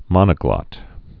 (mŏnə-glŏt)